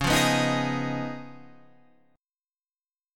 C# 9th